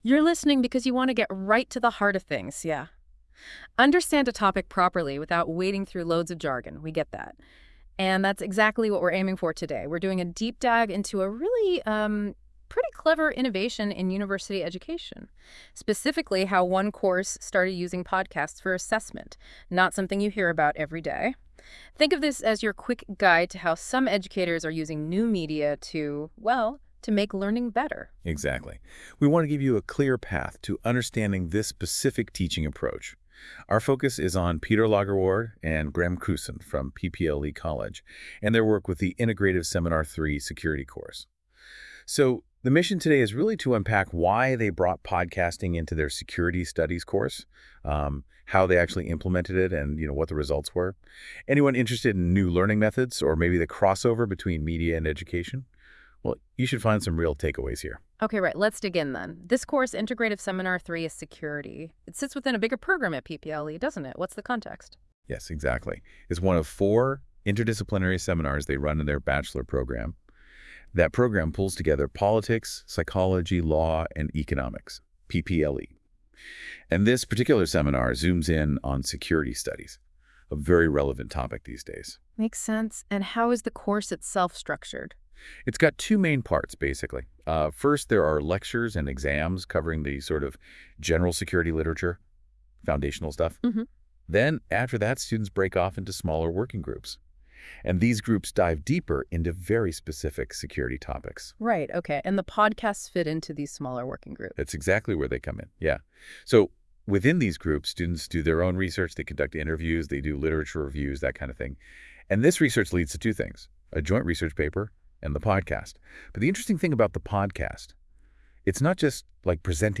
Luister op basis van bovenstaande tekst naar de (door AI gegeneerde) podcast over podcasts als reflectievorm.